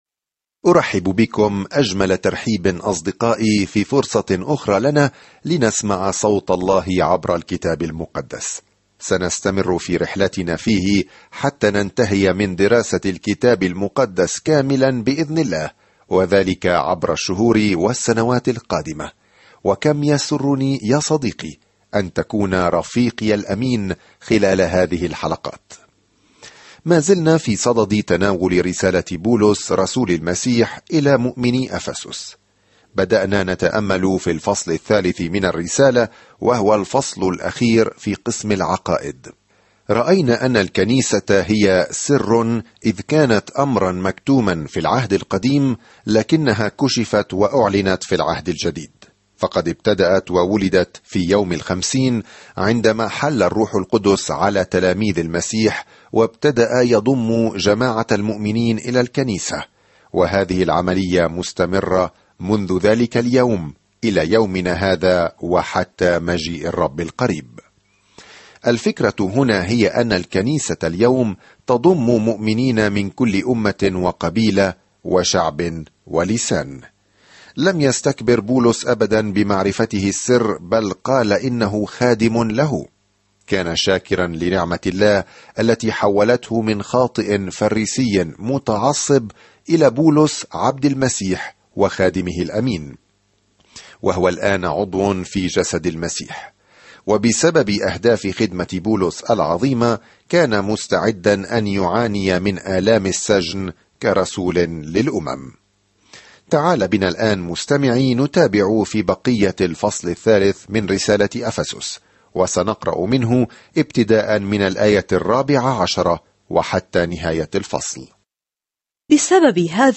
الكلمة أَفَسُسَ 14:3-21 يوم 14 ابدأ هذه الخطة يوم 16 عن هذه الخطة من الأعالي الجميلة لما يريده الله لأولاده، تشرح الرسالة إلى أهل أفسس كيفية السلوك في نعمة الله وسلامه ومحبته. سافر يوميًا عبر رسالة أفسس وأنت تستمع إلى الدراسة الصوتية وتقرأ آيات مختارة من كلمة الله.